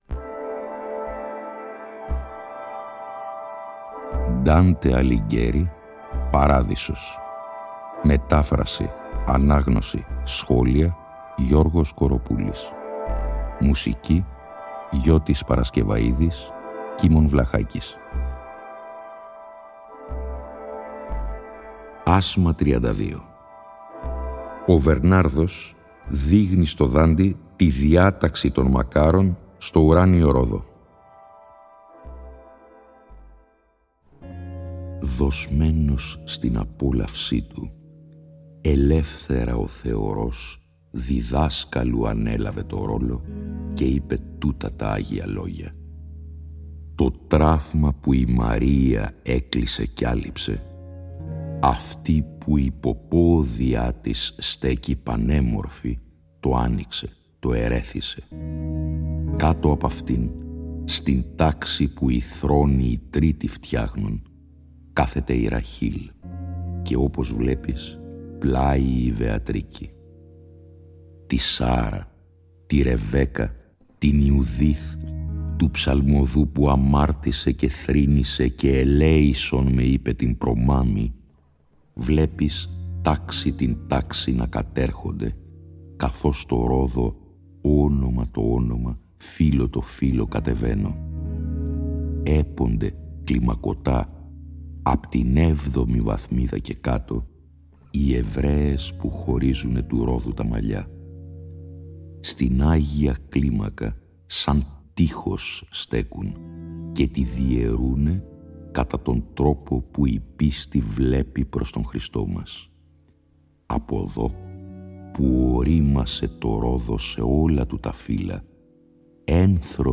Η ανάγνωση των 33 ασμάτων του «Παραδείσου», σε 21 ημίωρα επεισόδια, (συνέχεια της ανάγνωσης του «Καθαρτηρίου», που είχε προηγηθεί) συνυφαίνεται και πάλι με μουσική την οποία συνέθεσαν ο Γιώτης Παρασκευαΐδης και ο Κίμων Βλαχάκης, που ανέλαβε και την επιμέλεια ήχου. Η μετάφραση τηρεί τον ενδεκασύλλαβο στίχο και υποτυπωδώς την terza rima του πρωτοτύπου – στο «περιεχόμενο» του οποίου παραμένει απολύτως πιστή.